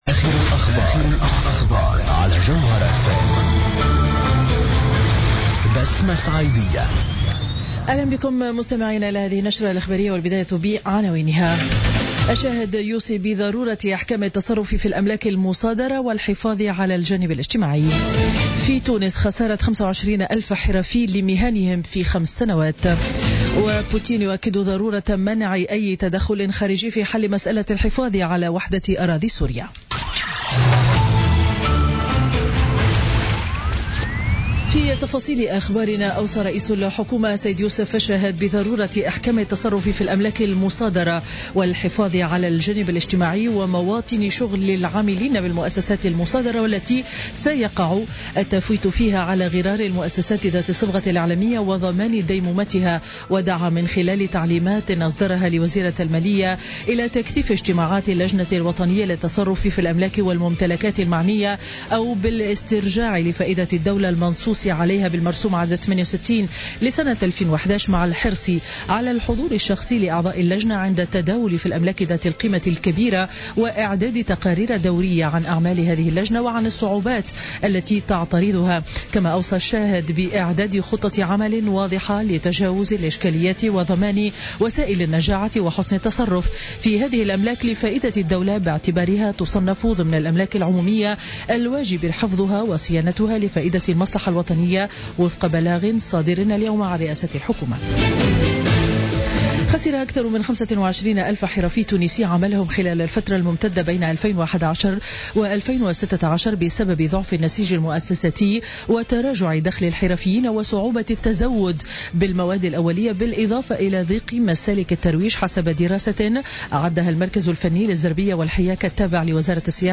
Journal Info 19h00 du jeudi 23 février 2017